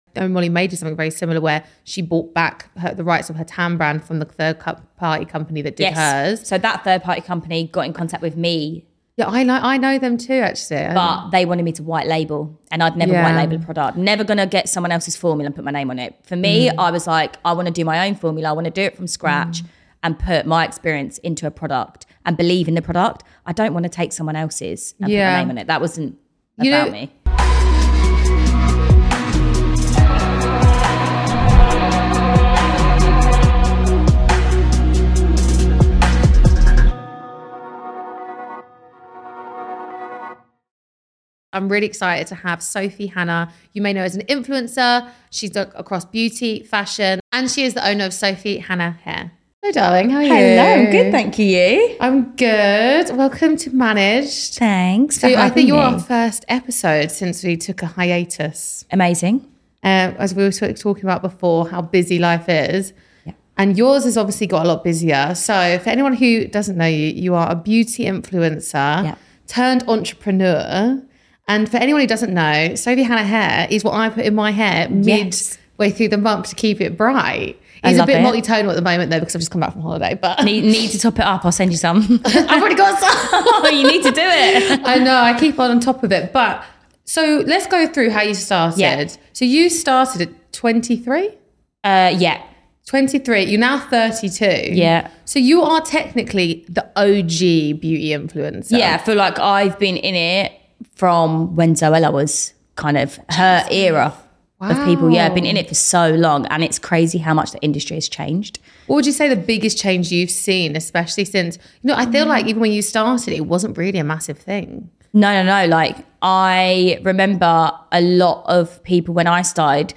Managed is a podcast/ digital show interviewing industry leaders across media, business, sports and more who found success at a young age.